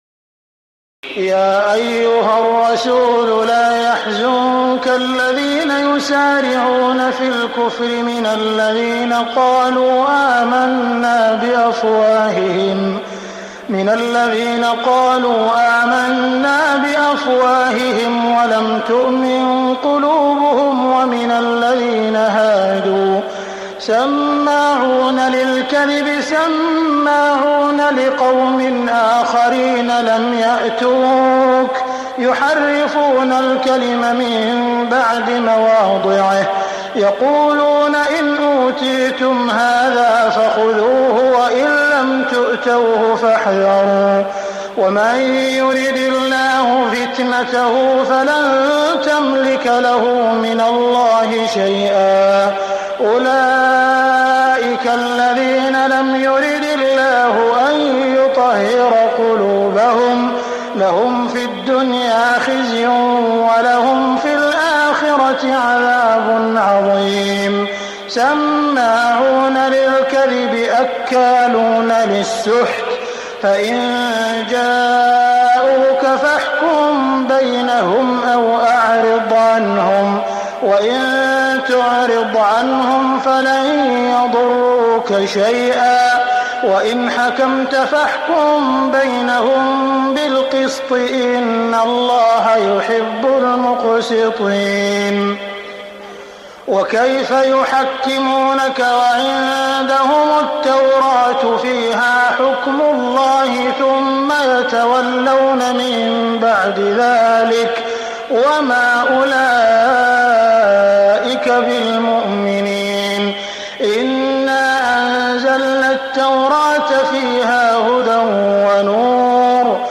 تهجد ليلة 26 رمضان 1418هـ من سورة المائدة (41-81) Tahajjud 26 st night Ramadan 1418H from Surah AlMa'idah > تراويح الحرم المكي عام 1418 🕋 > التراويح - تلاوات الحرمين